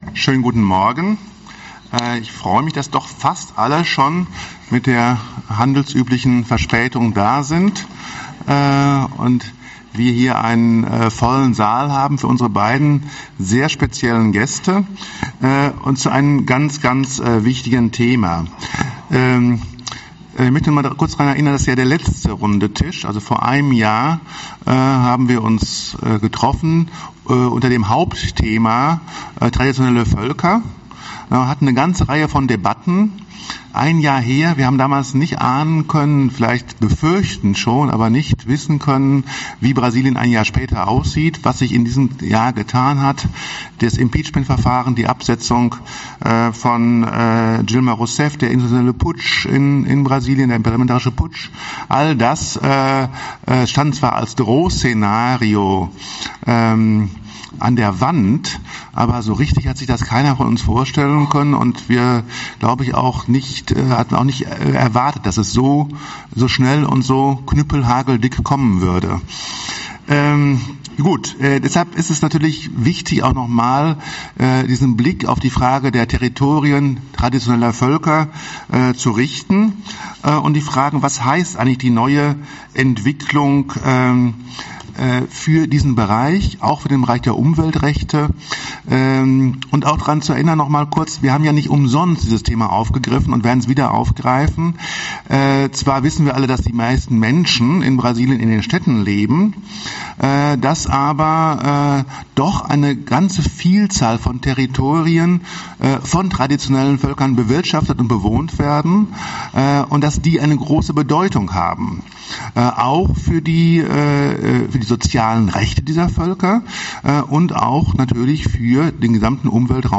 Diskussion:
Audio-Aufnahme des Plenums | Download (mp3) Protokoll (PDF) Bilder: